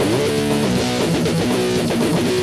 We were trying for a punk sound.